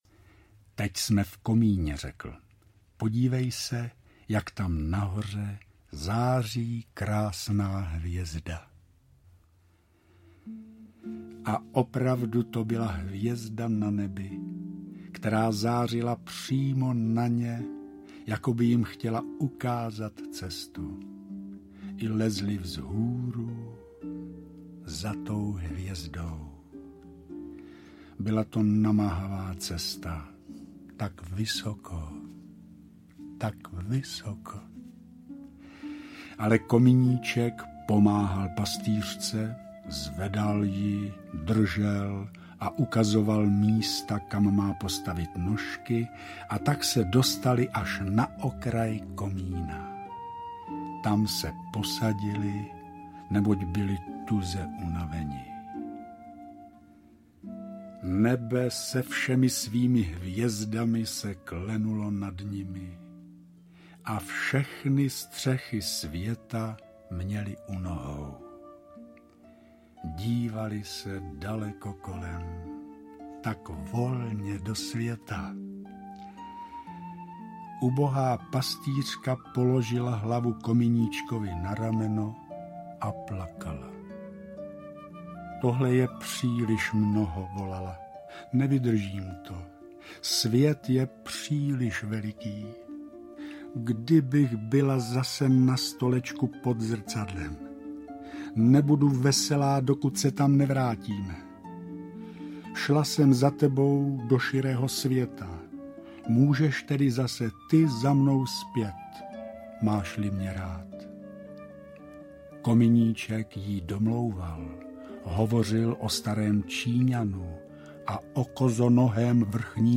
Vějíř pohádek audiokniha
Audiokniha Vějíř pohádek - obsahuje pohádky Hanse Christiana Andersena, které vypravuje Radovan Lukavský.
Ukázka z knihy